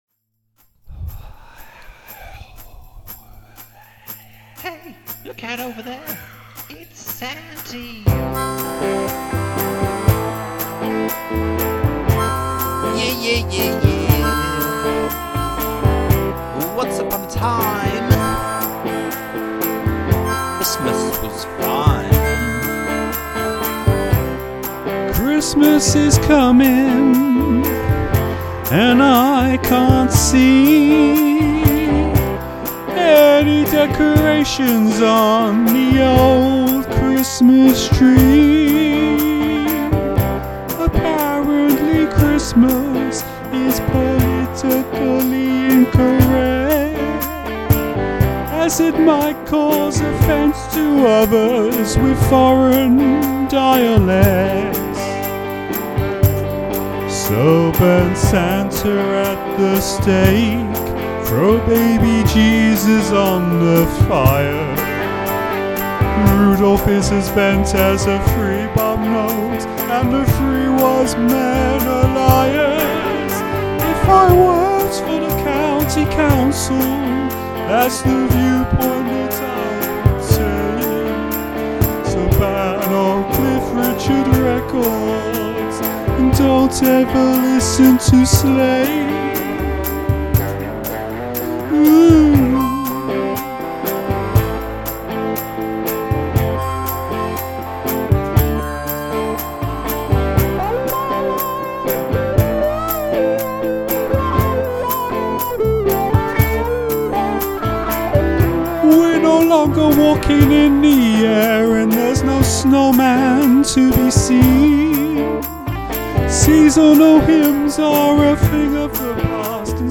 Wind effect by outdoors
harmonica